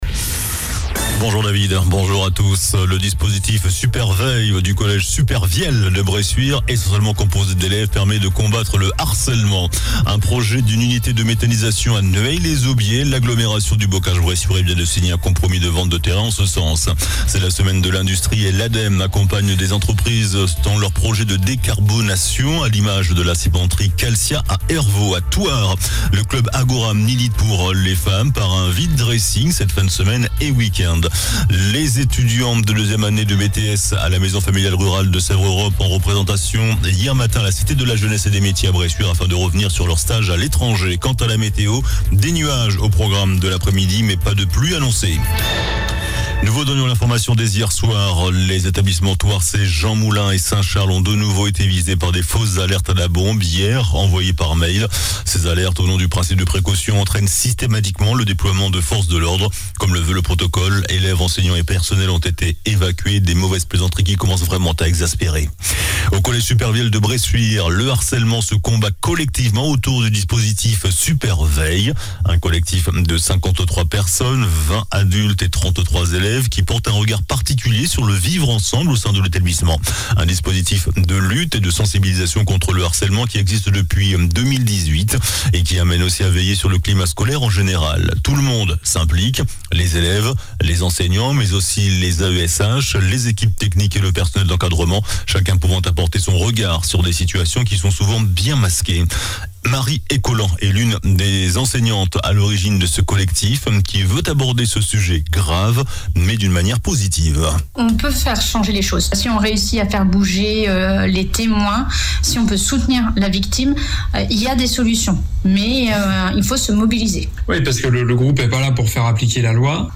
JOURNAL DU JEUDI 30 NOVEMBRE ( MIDI )